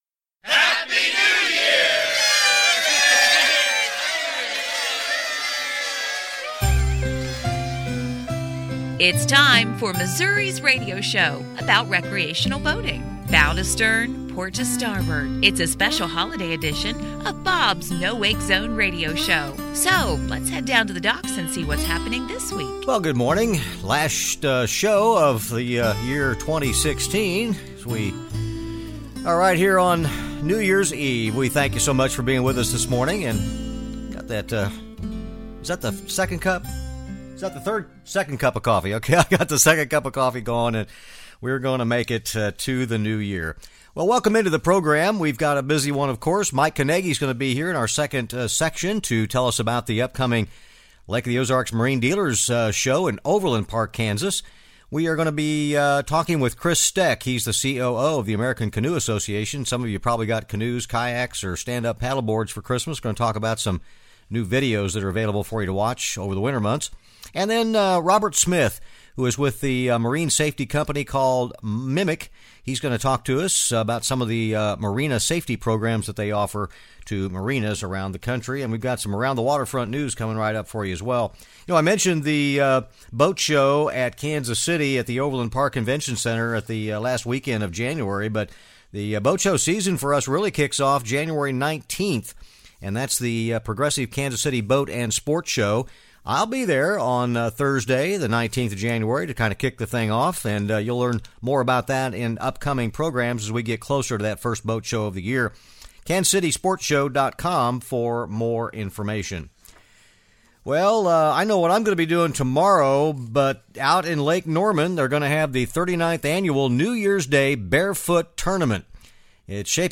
A packed New Year’s Eve Special from the Lake of the Ozarks.